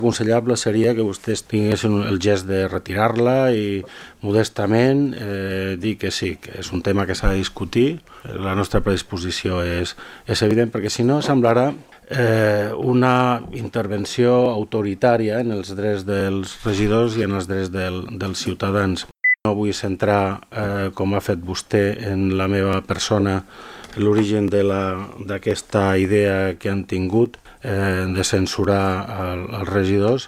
Recuperem les seves intervencions durant la sessió d’abril:
Sebastián Tejada, portaveu Calella en Comú Podem: